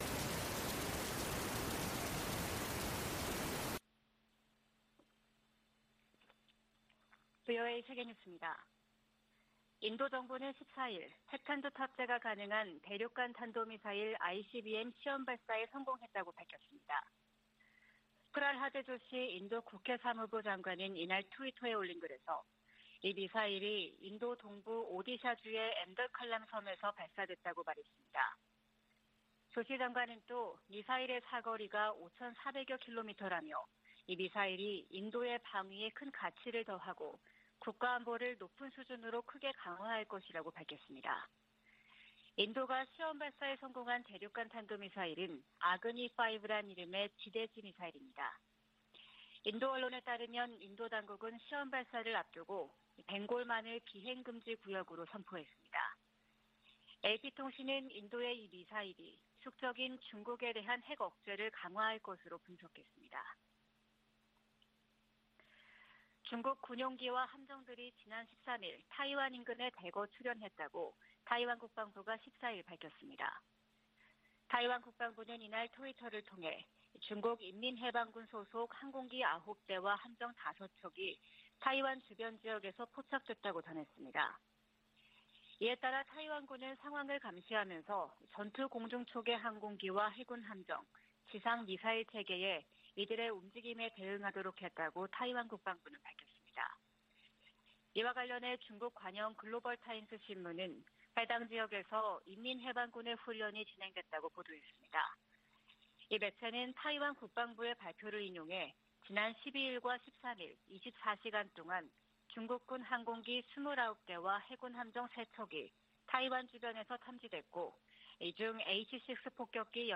VOA 한국어 '출발 뉴스 쇼', 2022년 12월 16일 방송입니다. 북한 김정은 정권이 주민을 착취해 무기 프로그램을 증강하고 있다고 국무부가 지적했습니다. 미국 의회가 새 국방수권법 합의안에서 핵전력을 현대화하고 미사일 방어를 강화하기 위한 예산을 계속 지원하기로 했습니다.